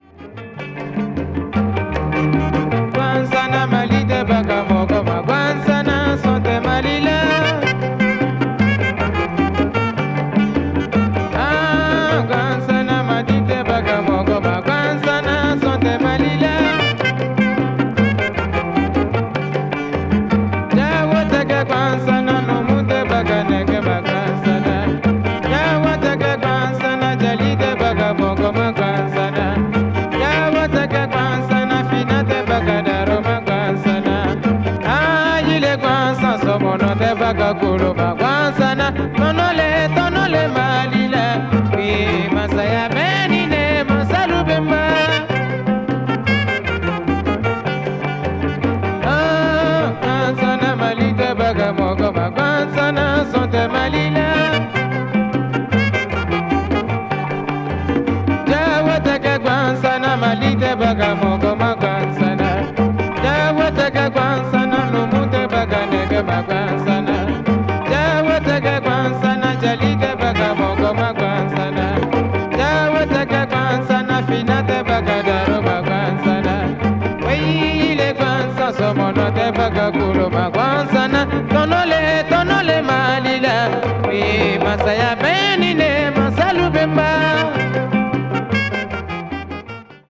afro groove   griot   mali   west africa   world music